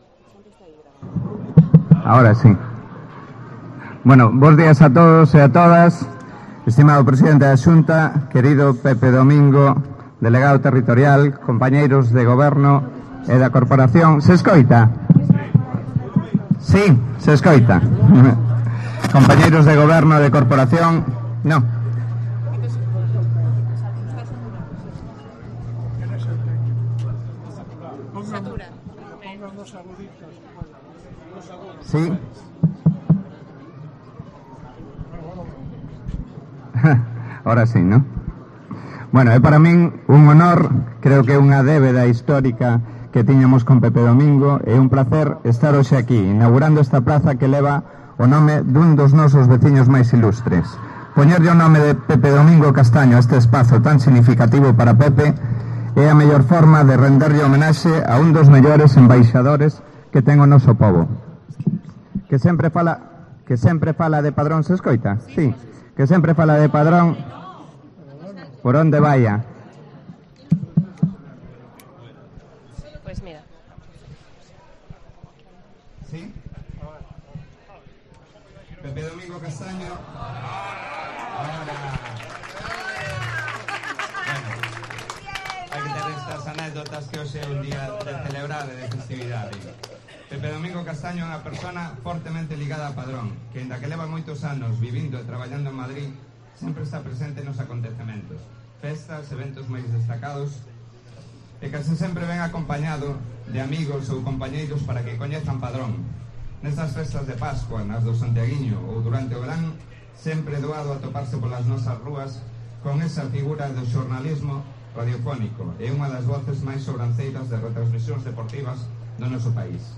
El Alcalde de Padrón, en su discurso